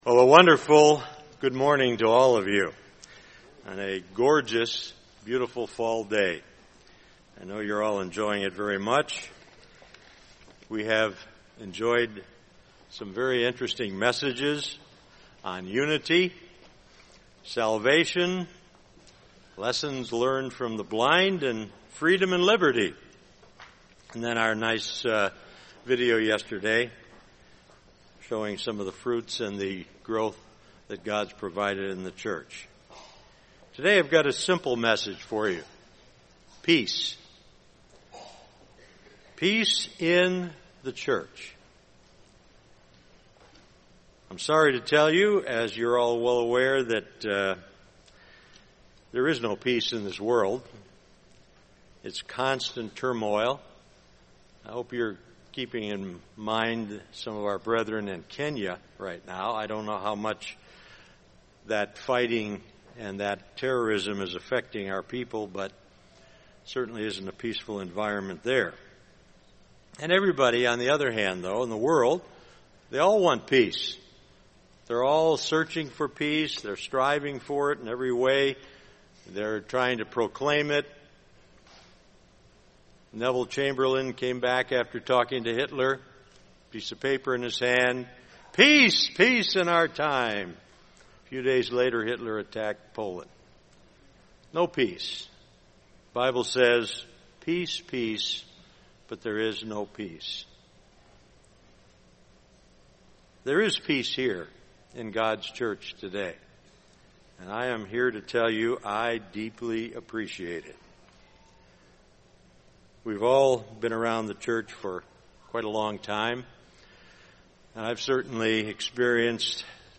This sermon was given at the Wisconsin Dells, Wisconsin 2013 Feast site.